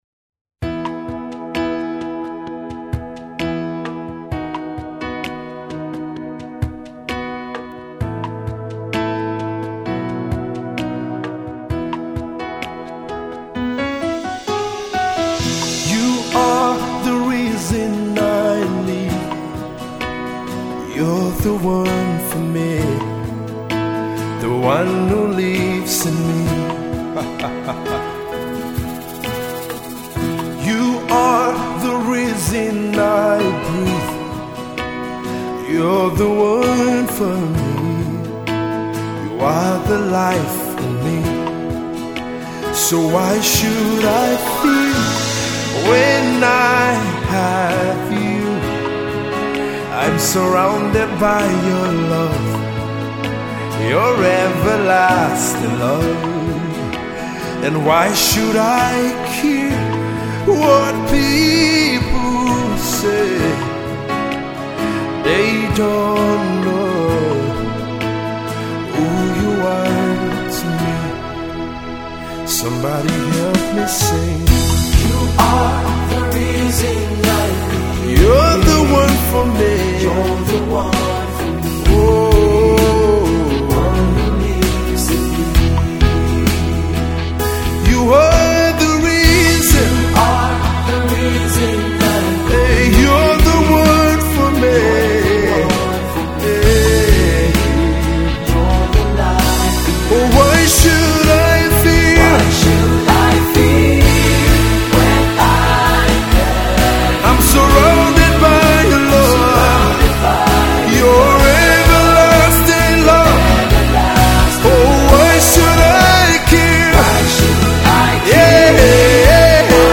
Gospel music
worship single